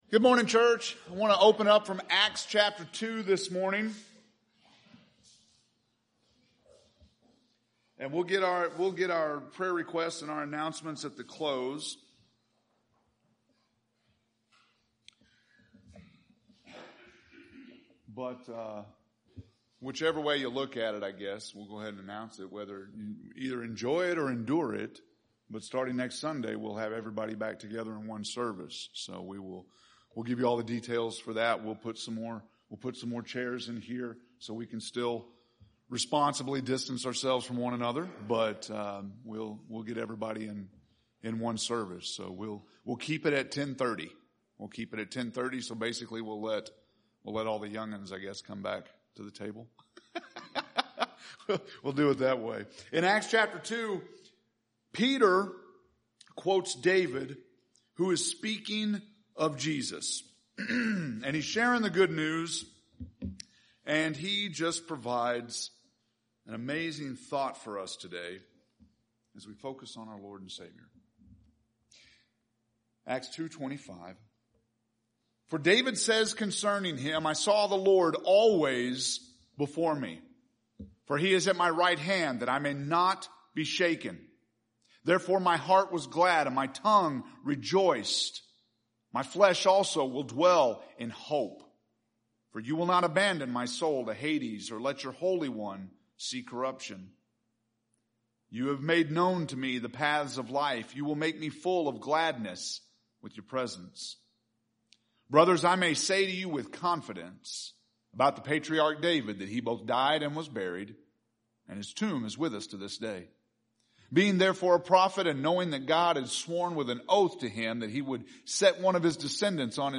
August 30th – Sermons